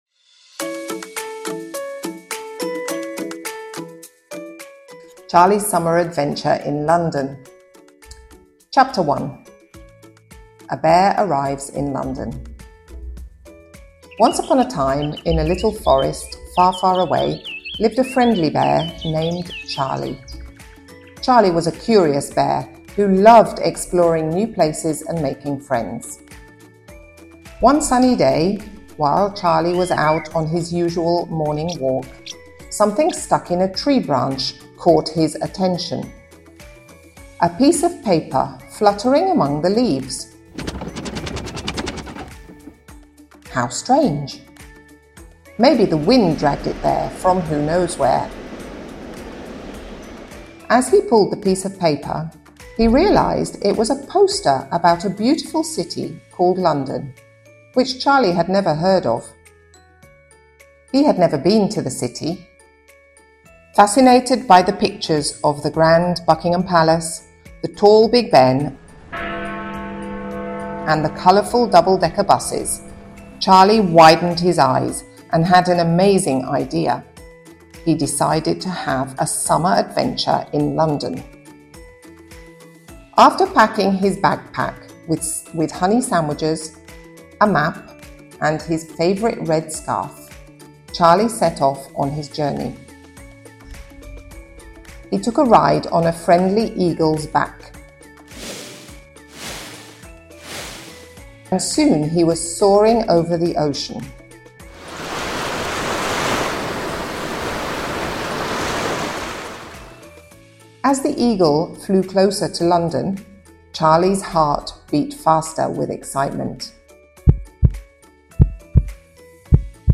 Short story for children in English
sound effects from Pixabay